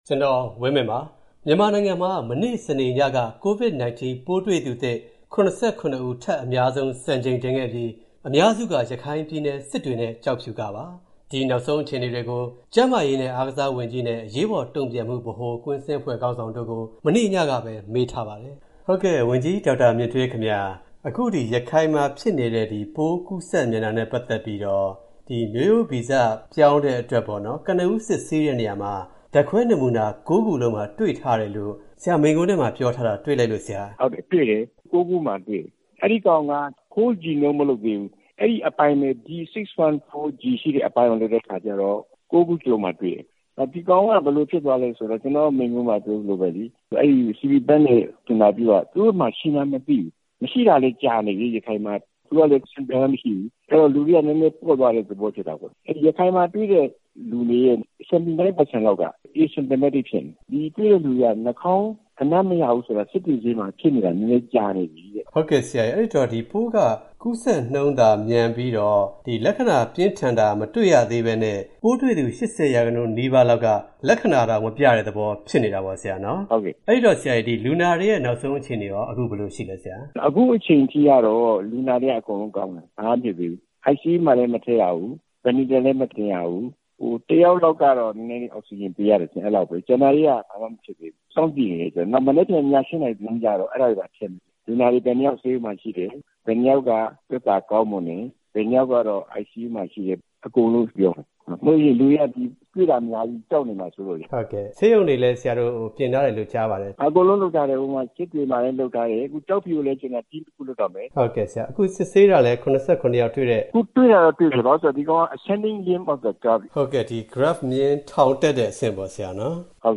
ရခိုင် COVID နောက်ဆုံးအခြေအနေ ကျန်းမာရေးဝန်ကြီး၊ အရေးပေါ် တုံ့ပြန်ကိုင်တွယ်မှု ခေါင်းဆောင်တို့နဲ့ မေးမြန်းခန်း